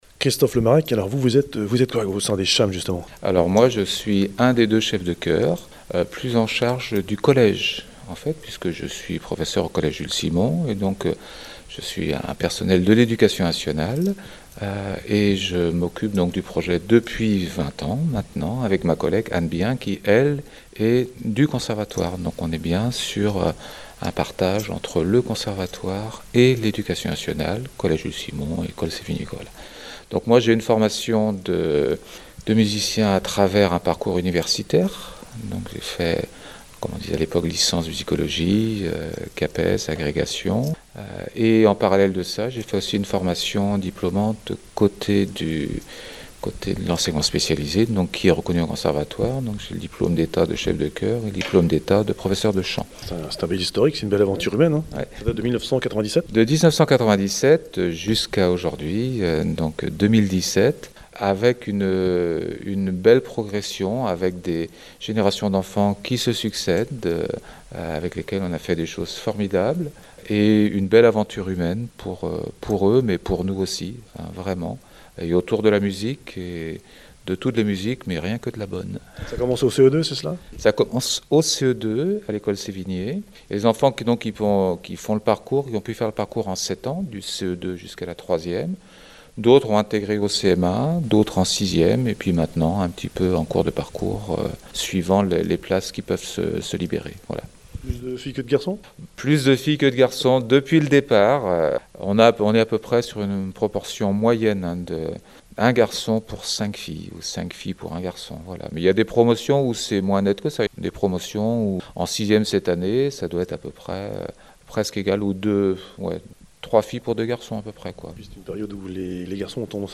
Présentation de la CHAM et de la soirée